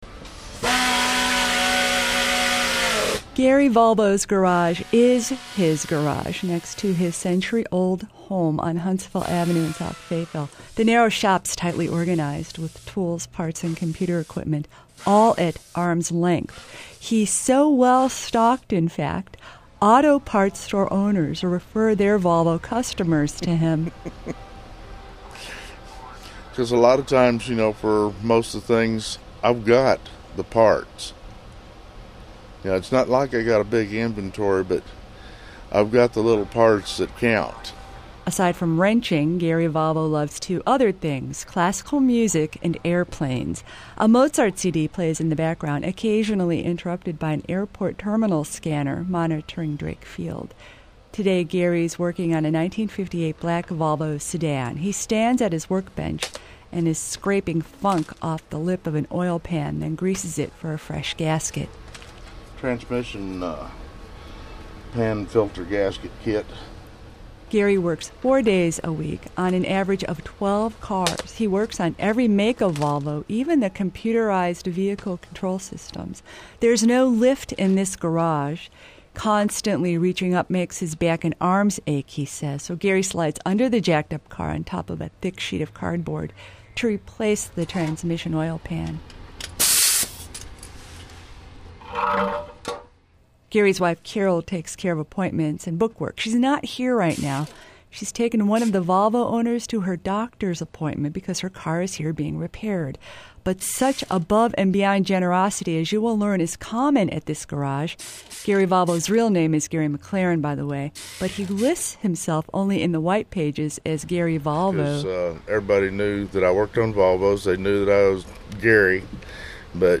We spend the morning in the garage of a savant mechanic